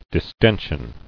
[dis·ten·sion]